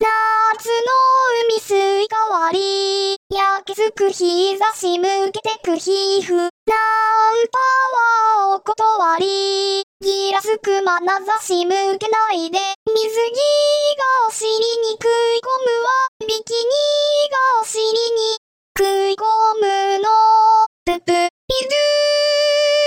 投稿してもらった歌詞は、UTAUを用いて重音テトさんに歌ってもらい、そのデータを質問文中のアドレス（はてなグループのページ）にアップします。
・こだわると大変なのでUTAわせるときは、「おま☆かせ」くらいの調声しかしません。